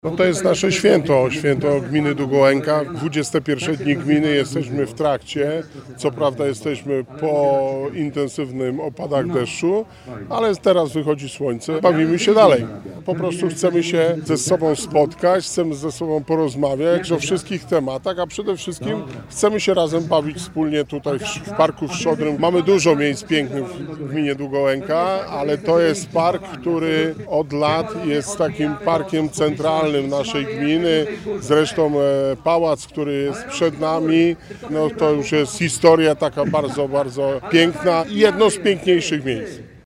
O idei wydarzenia mówi Wojciech Błoński, wójt Gminy Długołęka.